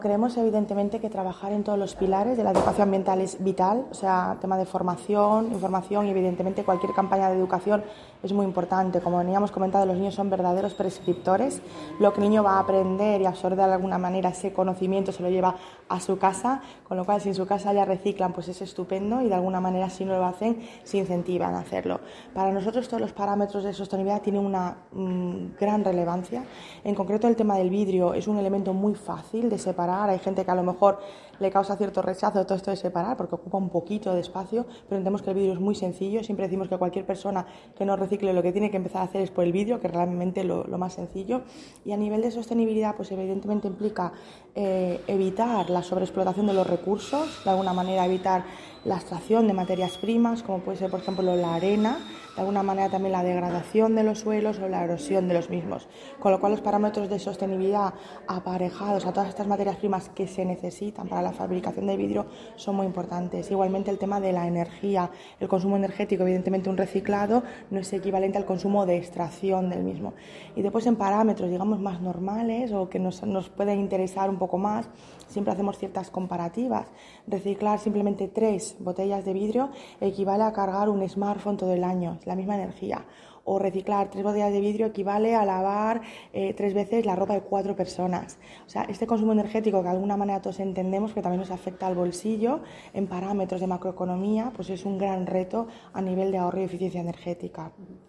La secretaria autonómica de Energía, Sostenibilidad y Acción Climática, María Cruz Ferreira, presenta ‘Los Peque Recicladores’, campaña de reciclaje de vidrio en colegios de diferentes municipios.